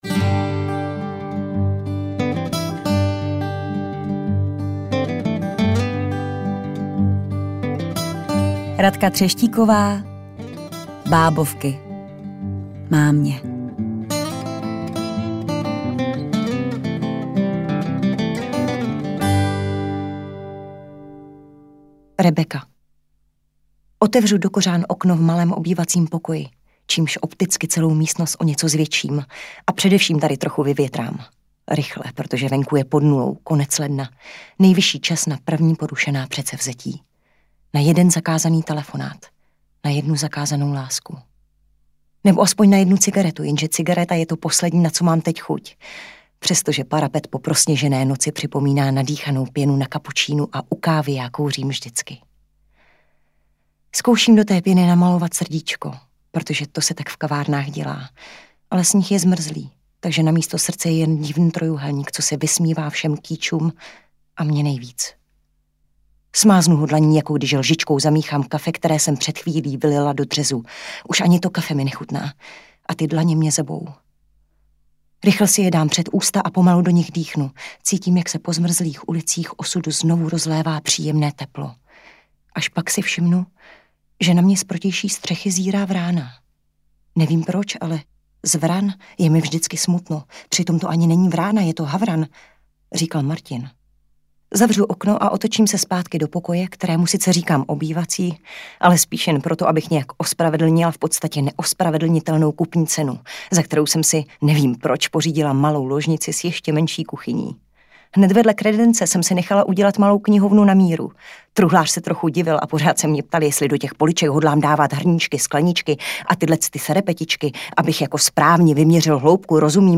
Dvanáct žen, dvanáct osudů a dvanáct hlasů! Napadlo vás někdy, co řeší milenka ženatého muže a jaká je ve skutečnosti ta příšerná manželka, o které jí on vypráví?
Herecky, ktere jednotlive kapitoly namlouvaji postavam vdechly zivot a naprosto uzasne je interpretuji.
AudioKniha ke stažení, 12 x mp3, délka 8 hod. 56 min., velikost 489,5 MB, česky